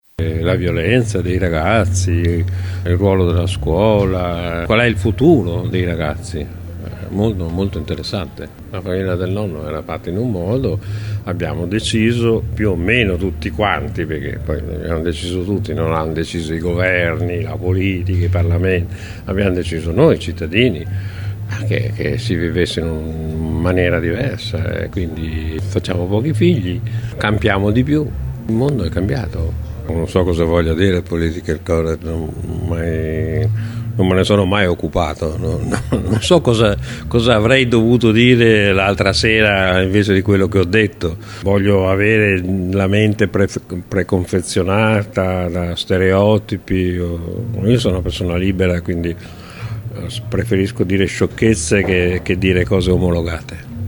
Crepet con il suo stile sempre provocatorio e pungente ha fatto riflettere spesso in maniera amara sul ruolo genitoriale, sugli errori che si commettono, con uno sguardo sempre un pò volto al passato da cui dovremo sempre cercare di prendere i riferimenti che possano aiutare a far maturare il nostro cervello.
crepet_intervista.mp3